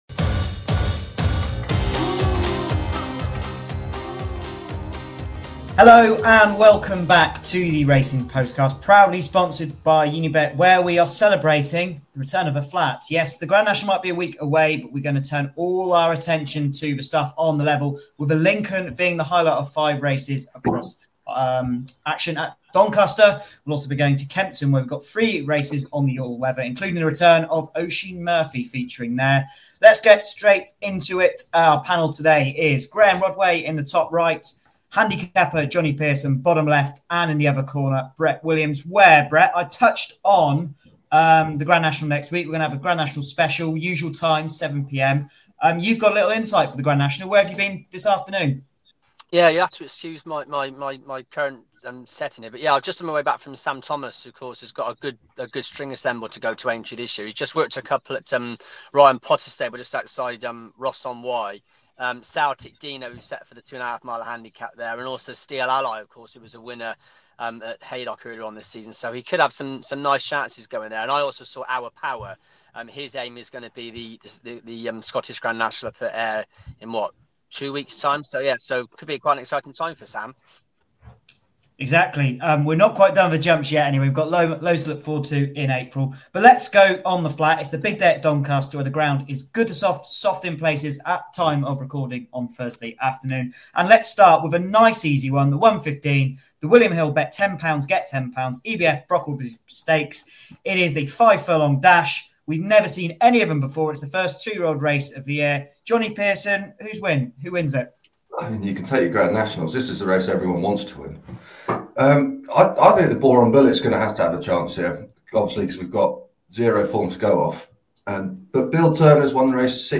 The Flat is back! It’s Lincoln day at Doncaster and we have a cracking panel in place to preview the big one and every other race on ITV4 this Saturday.